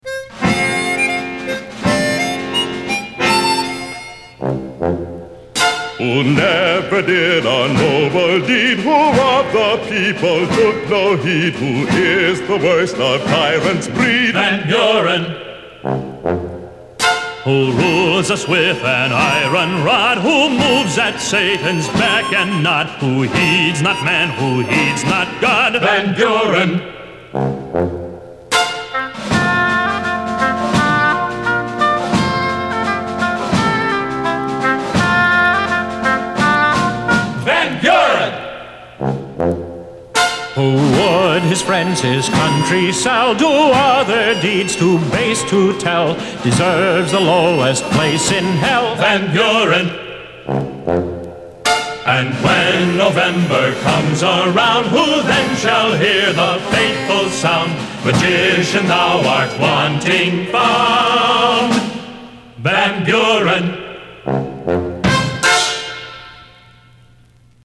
All the votes are in (barring Guam, and some more rural counties) and the winner of the grand MP3 Monday election for President's Day is: "Van Buren" - An anti-Van Buren song from the 1964 LIFE magazine album Sing Along With Millard Fillmore, featuring their recordings of real campaign songs throughout the decades. From the 1840 election, this song is a one big eff you to Martin Van Buren from Willam Henry Harrison and the Whig party.